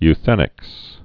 (y-thĕnĭks)